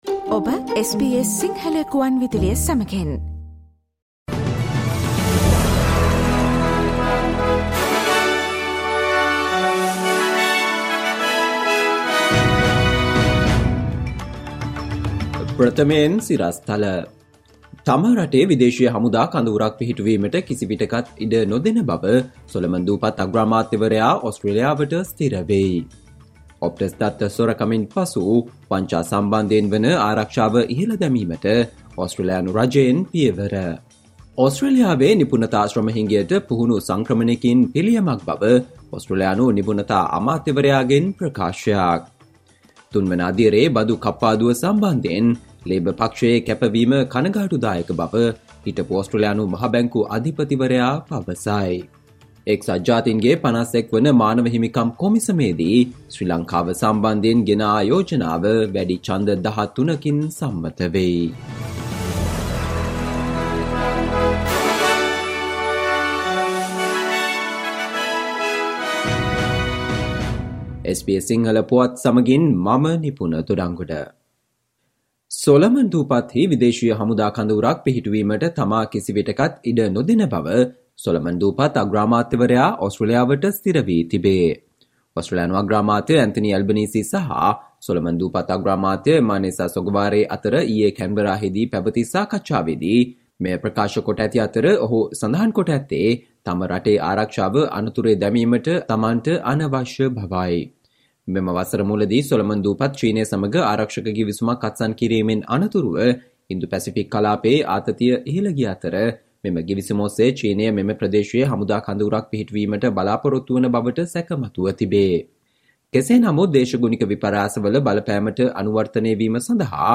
Listen to the SBS Sinhala Radio news bulletin on Friday 07 October 2022